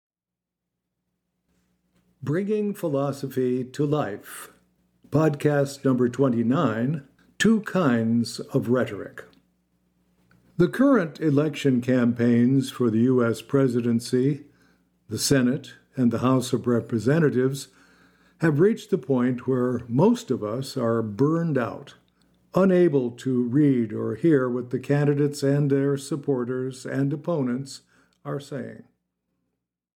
Two Kinds of Rhetoric (EN) audiokniha
Ukázka z knihy